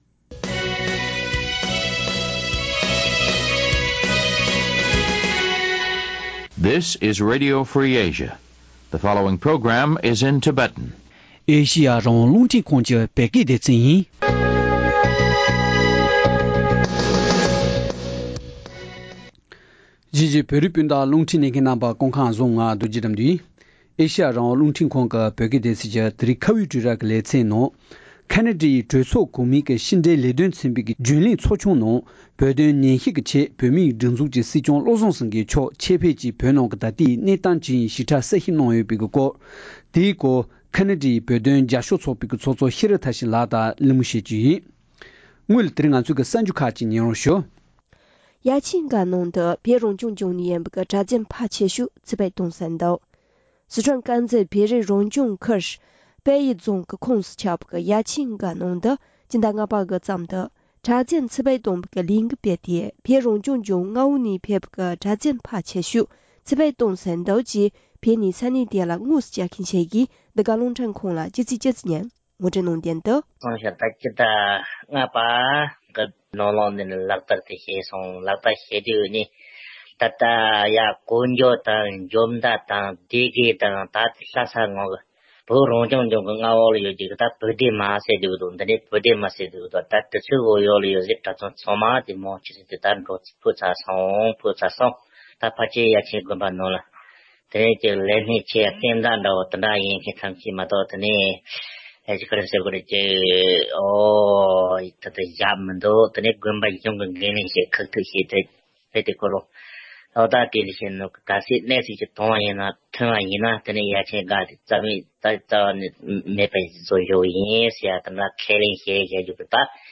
ཁེ་ན་ཌའི་གྲོས་ཚོགས་ནང་དབུ་མའི་ལམ་ལ་རྒྱབ་སྐྱོར་གྱི་གྲོས་འཆར་དང་སྲིད་སྐྱོང་མཆོག་གིས་གྲོས་ཚོགས་གོང་མའི་ནང་བོད་དོན་ཉན་ཞིབ་གནང་བའི་ཐད་གླེང་མོལ།